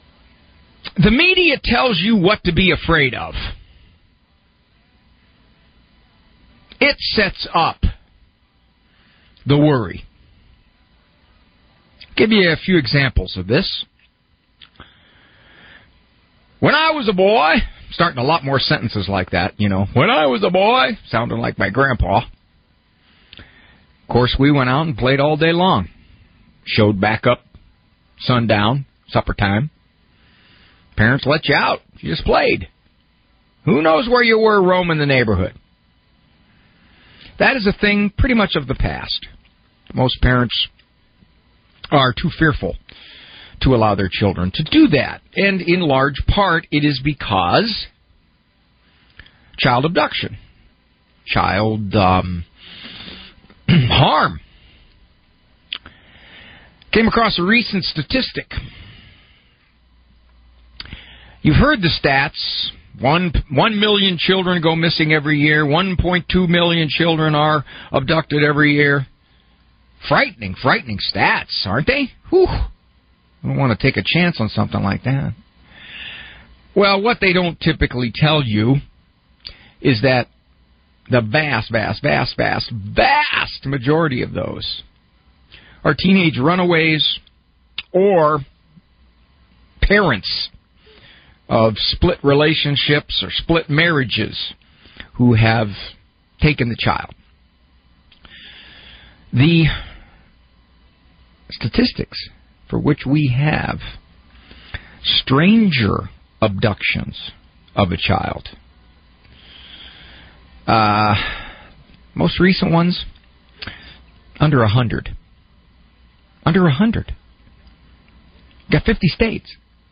Aug-31-Radio-Monologue.m4a